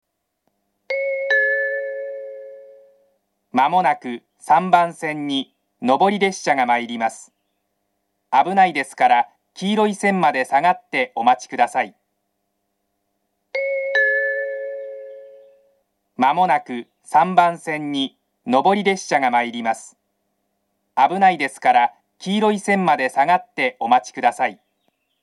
３番線接近放送 上り本線です。